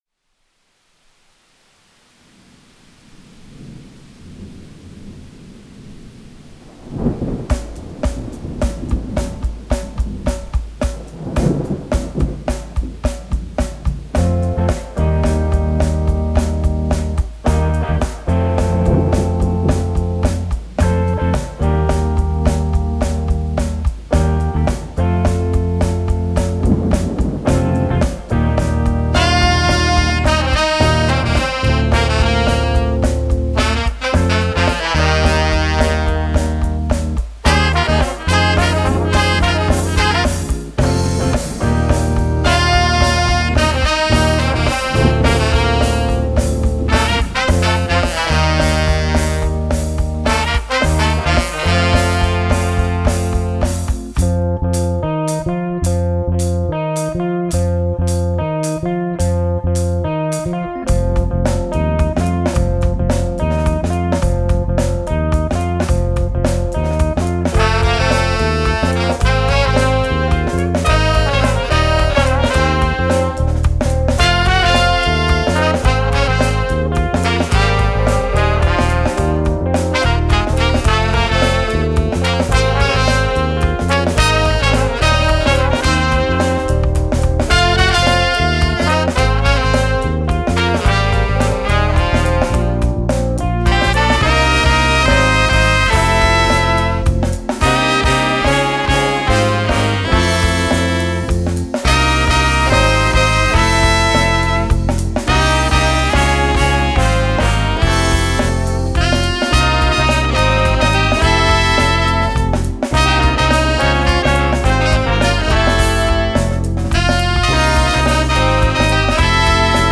Their big exuberant sound easily dominated the room
He mostly used a Fender Rhodes sound.
His drumming is tasteful and not over embellished.
electric bass
trombone